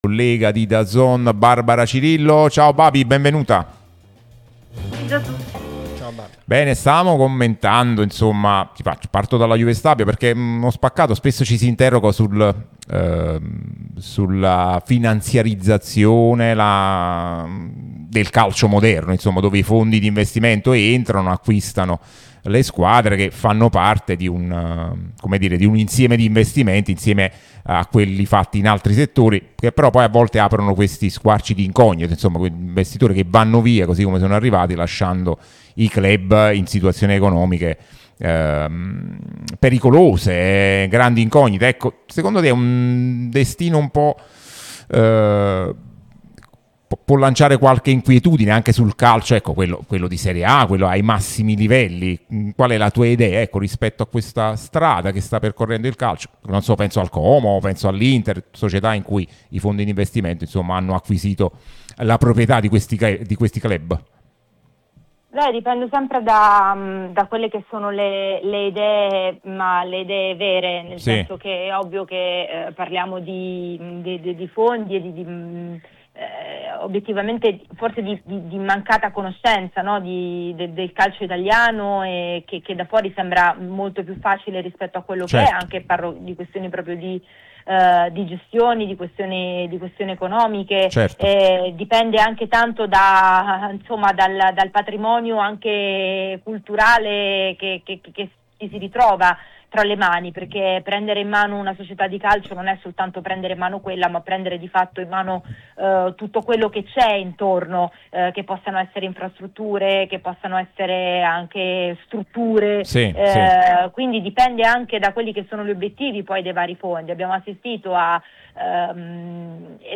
l'unica radio tutta azzurra e live tutto il giorno